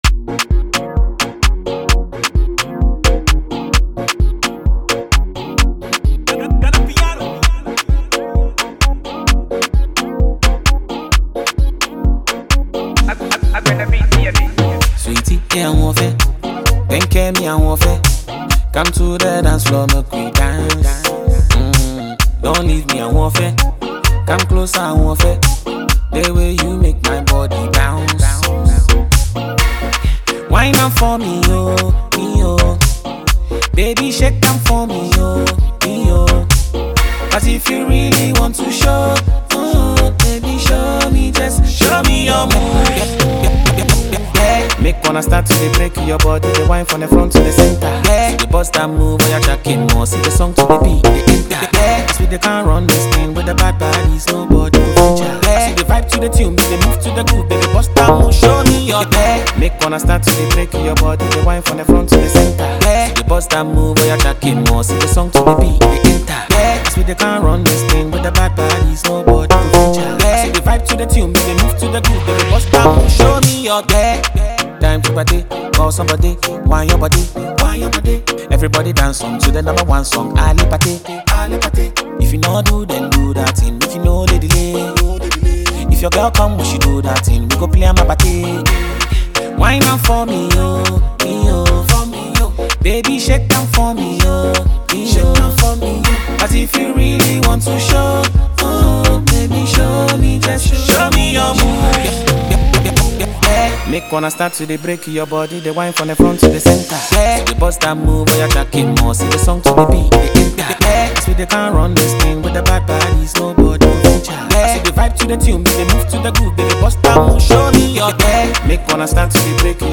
comes with a fast-paced groove, catchy bounce, and signature
Ghana Afrobeat MP3